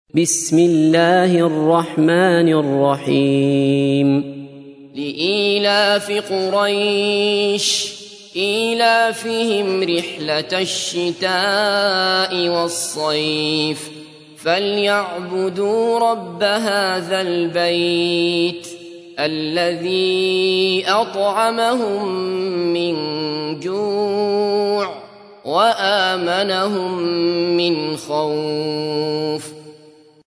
تحميل : 106. سورة قريش / القارئ عبد الله بصفر / القرآن الكريم / موقع يا حسين